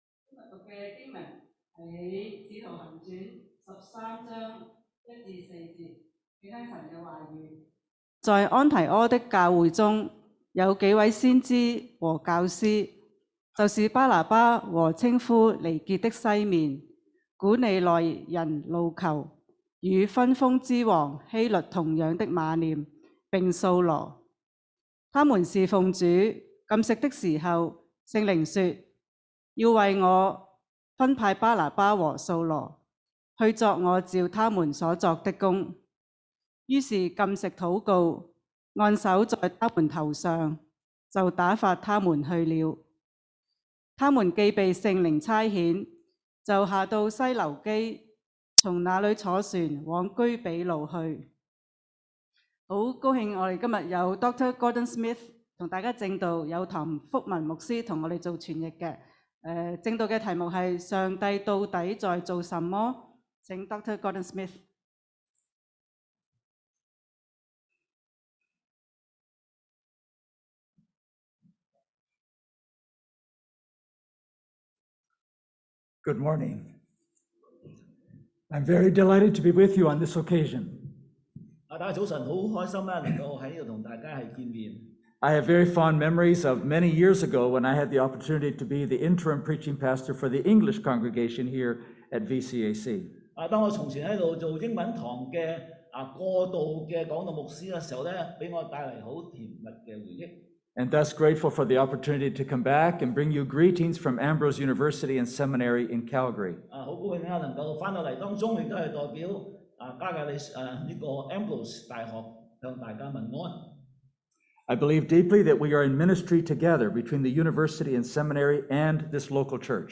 講道錄像